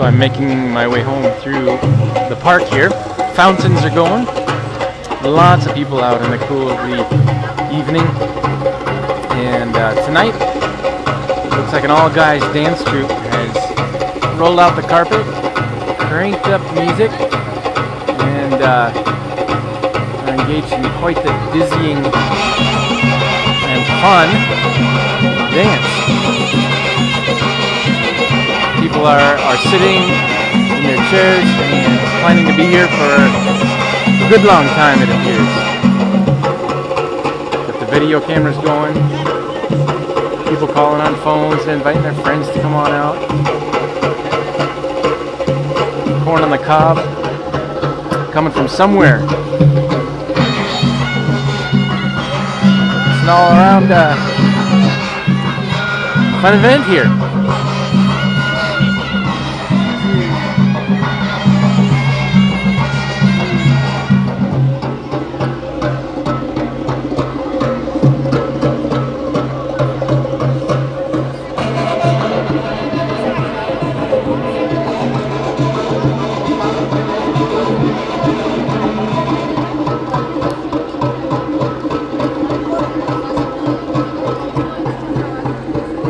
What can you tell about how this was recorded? In the park (high volume)